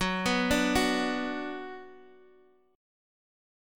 Listen to F#sus4#5 strummed